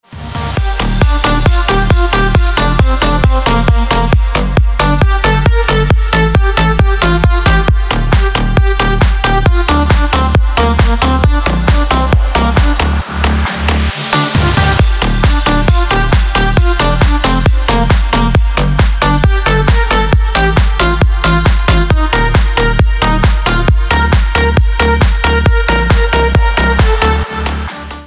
Громкие звонки, звучные рингтоны
Громкий трек на звонок